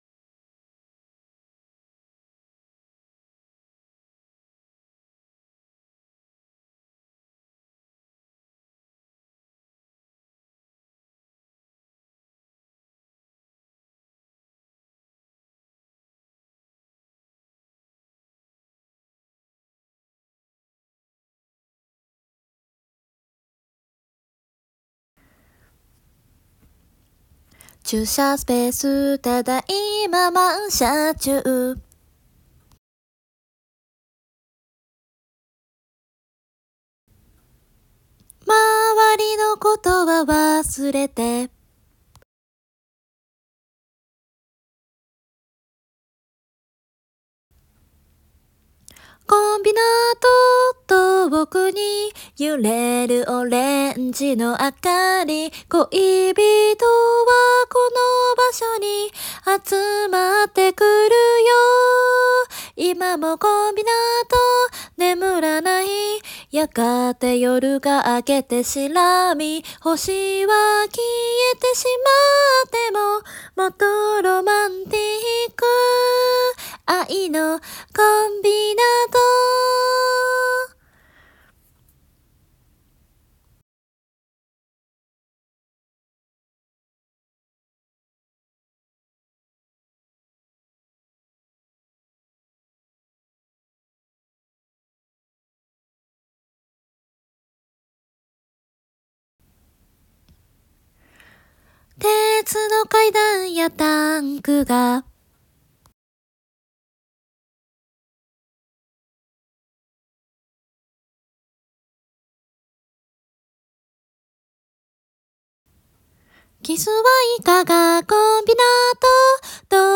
（アカペラ提出）を今すぐダウンロード＆リピート再生できます！